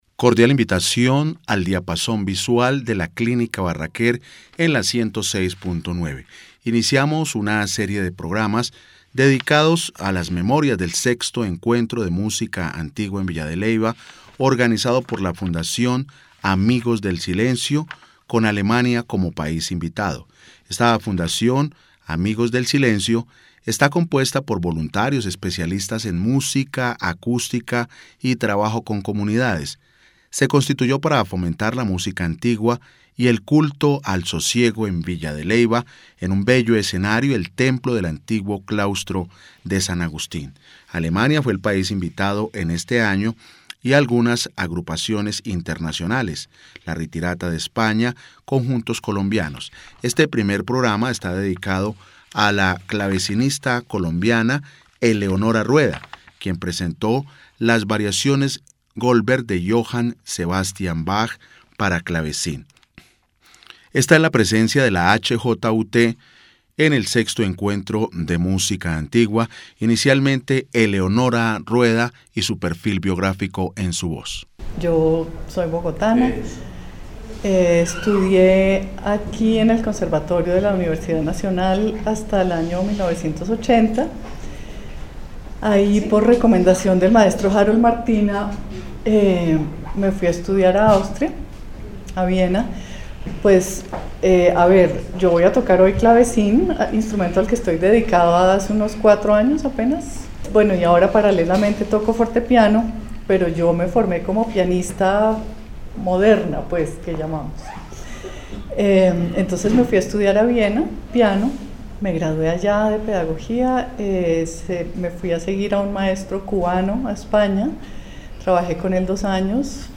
VI ENCUENTRO DE MÚSICA ANTIGUA EN VILLA DE LEYVA